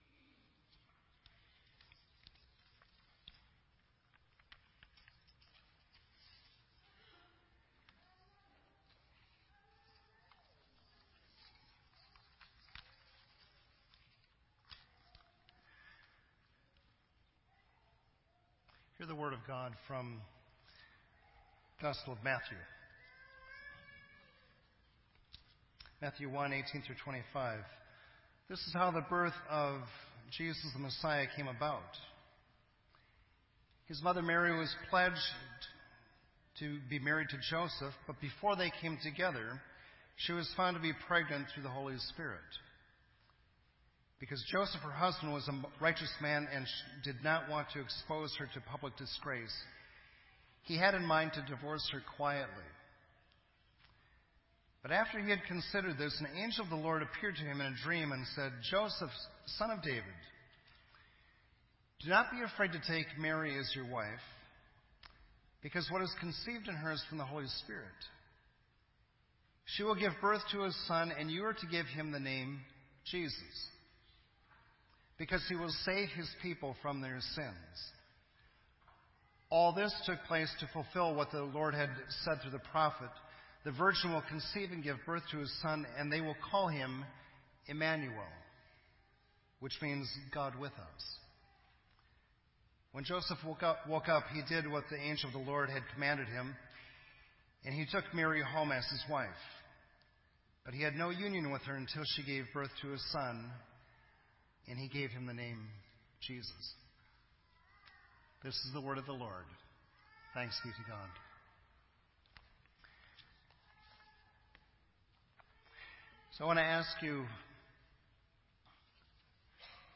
“Track 13” from 2016-12-24 Christmas Eve Service by Bloomington Covenant Church.
Genre: Speech.